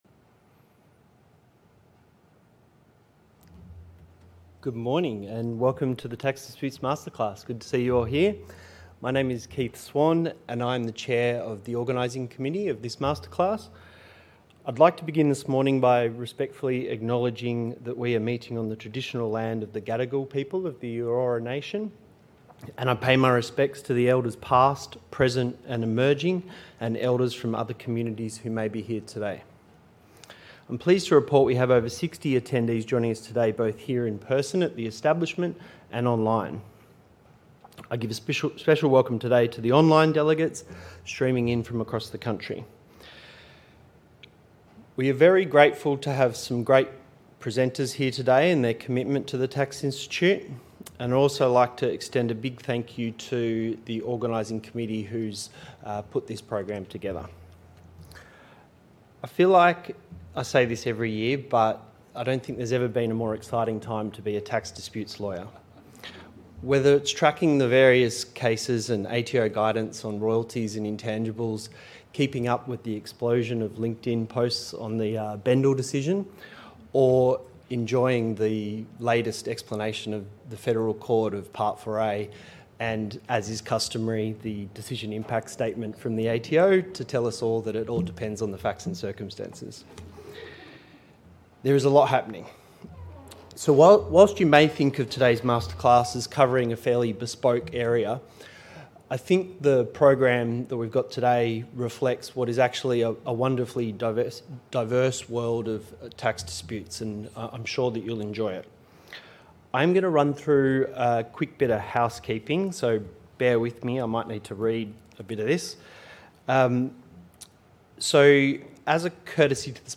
Event Name: Tax Disputes Masterclass
Took place at: The Establishment Sydney & Online